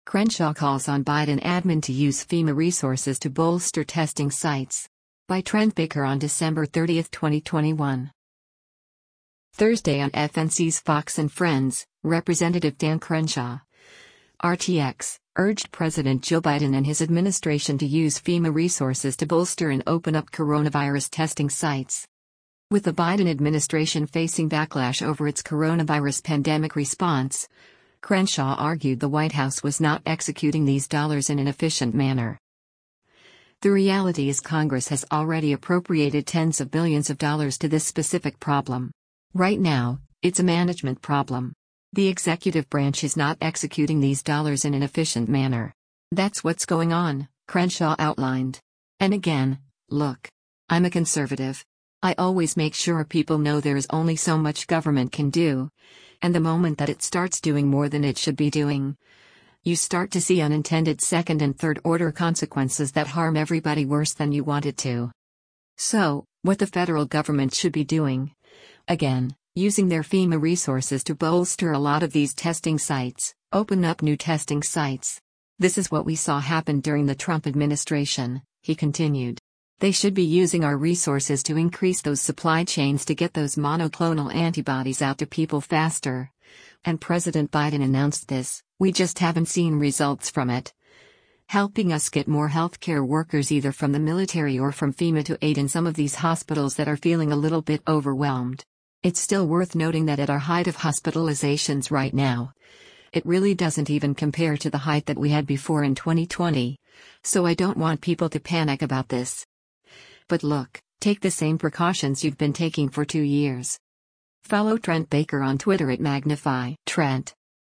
Thursday on FNC’s “Fox & Friends,” Rep. Dan Crenshaw (R-TX) urged President Joe Biden and his administration to use FEMA resources to bolster and open up coronavirus testing sites.